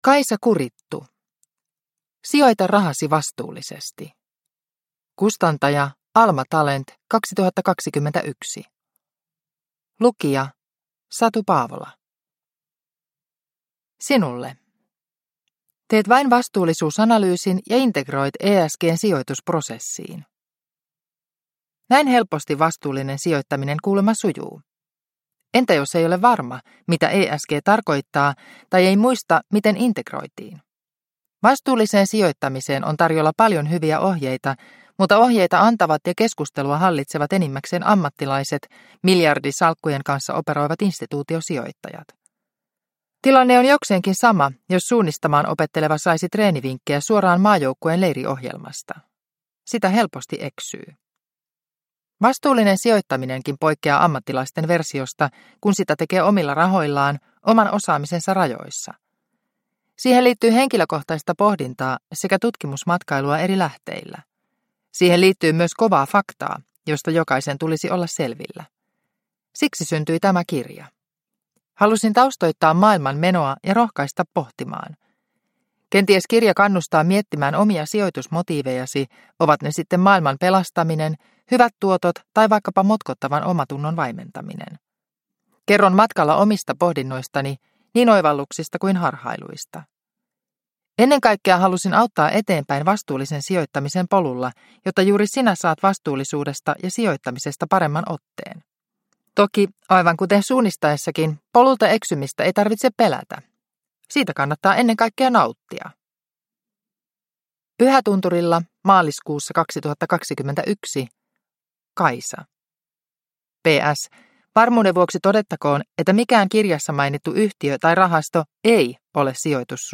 Sijoita rahasi vastuullisesti – Ljudbok – Laddas ner